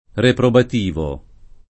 reprobat&vo] agg. — es.: la parola e il concetto di «barocco» nacquero con intento reprobativo [la par0la e il kon©$tto di «bar0kko» n#kkUero kon int$nto reprobat&vo] (Croce)